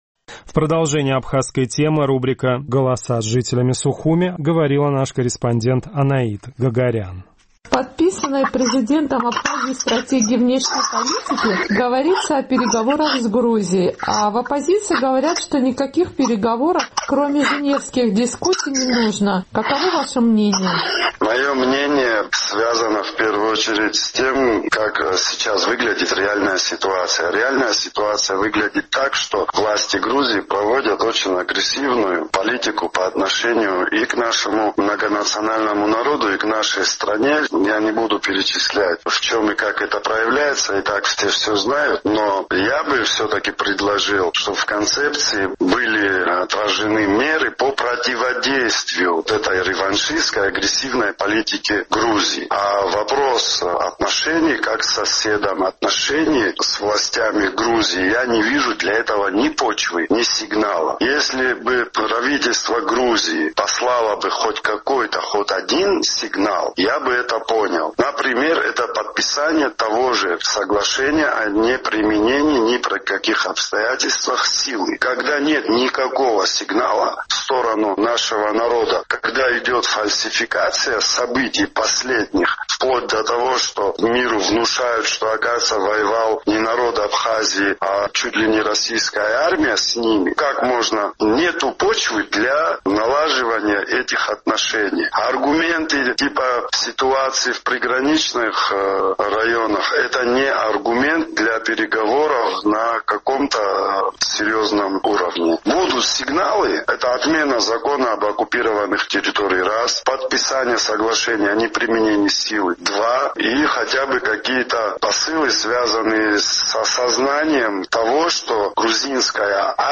К возможности ведения переговоров с Тбилиси в Абхазии относятся с очень большой осторожностью. Наш традиционный сухумский опрос.